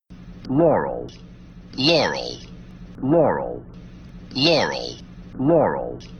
And here is that same file except that the 1st, 3rd, and 5th is put through a low-pass filter in which the cutoff frequency was 1500 hertz, and the rolloff was 36 decibels:
If you have trouble hearing "laurel", you should be able to hear it in that file.
yanny-laurel-low-pass-filter-on-1st-3rd-5th.mp3